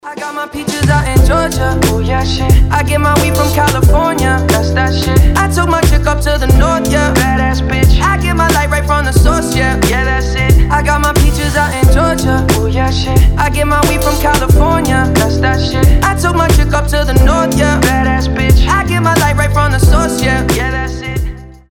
• Качество: 320, Stereo
мужской голос
забавные
регги
RnB
легкие